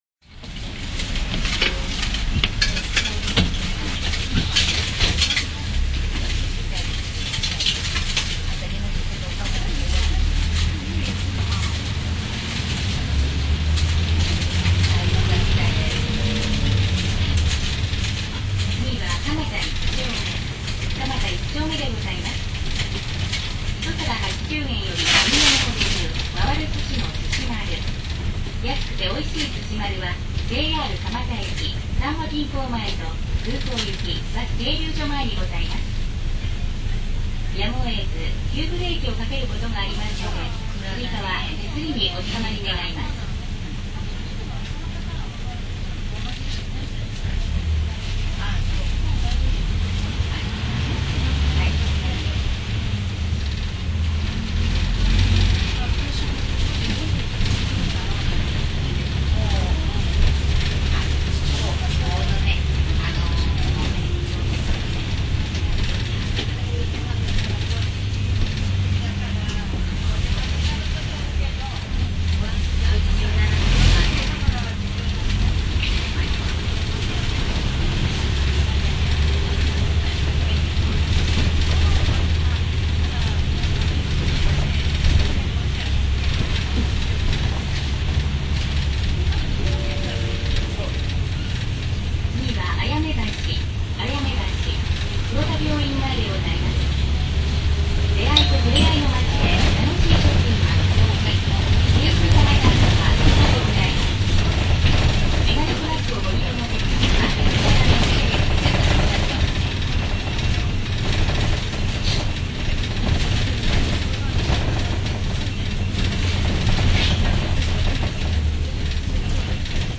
中扉閉時のエア音は圧巻です！